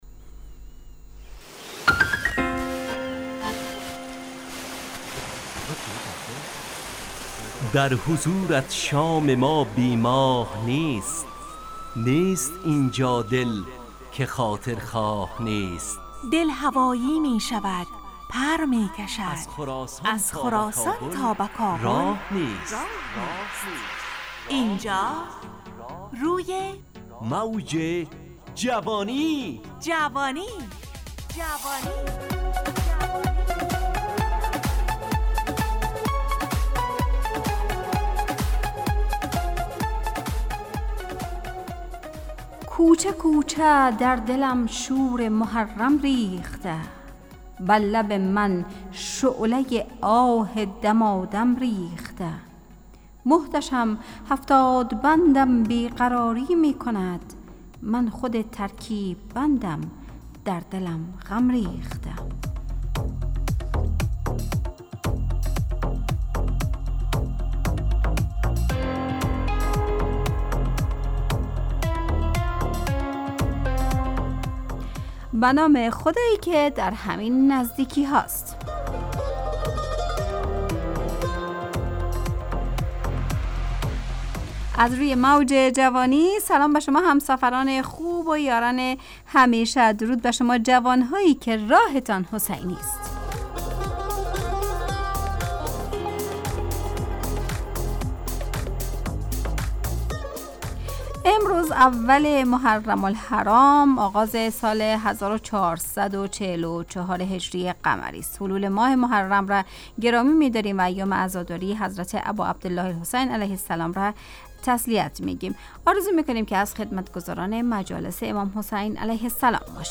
روی موج جوانی، برنامه شادو عصرانه رادیودری.
همراه با ترانه و موسیقی .